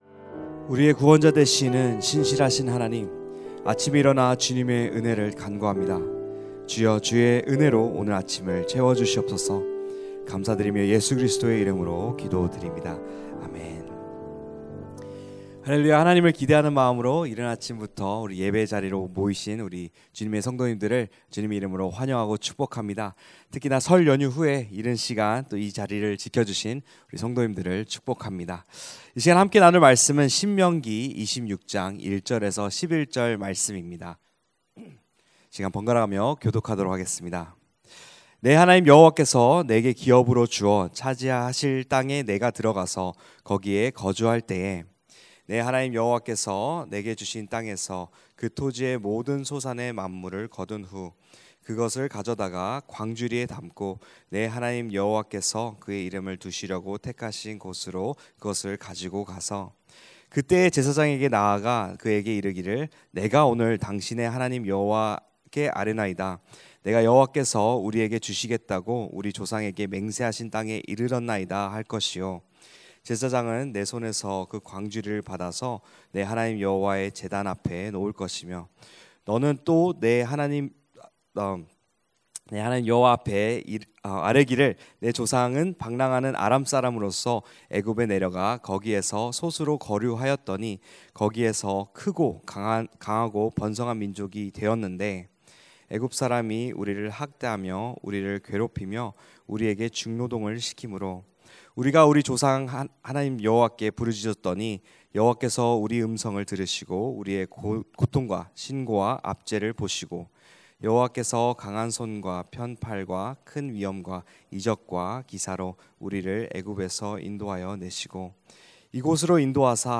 2026-02-19 새벽기도회 (신 26:1-11) 여호와 앞에 감사의 첫 열매를 드리라
> 설교